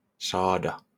Ääntäminen
IPA: /ˈsɑːdɑˣ/